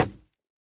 Minecraft Version Minecraft Version 1.21.5 Latest Release | Latest Snapshot 1.21.5 / assets / minecraft / sounds / block / bamboo_wood / break4.ogg Compare With Compare With Latest Release | Latest Snapshot
break4.ogg